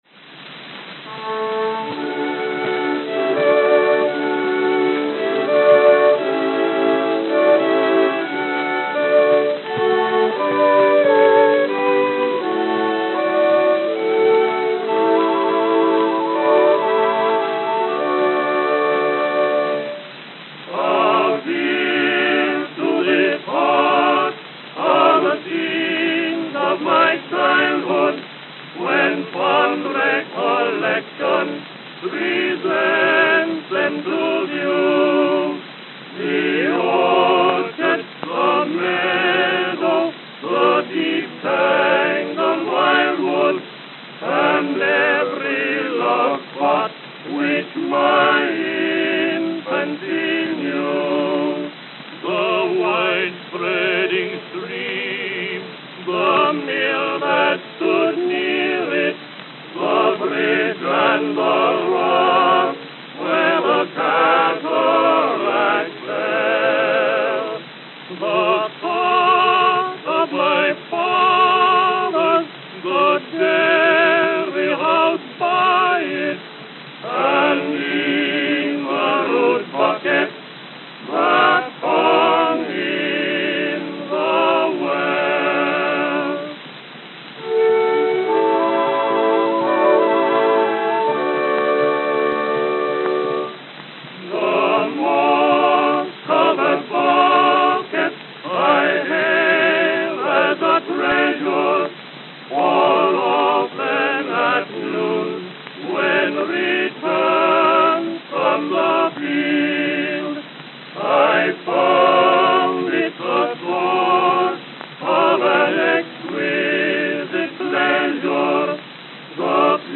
Edison Diamond Discs